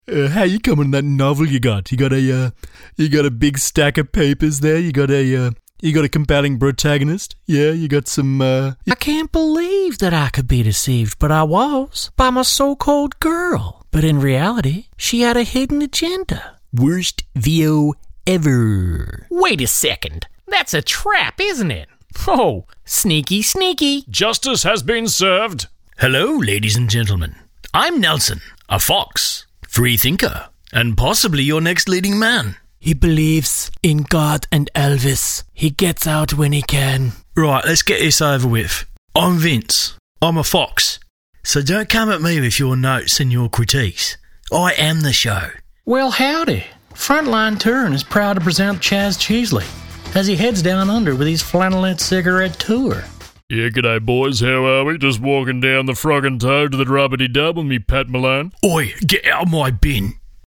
Cartoon & Character Voice Overs | The Voice Realm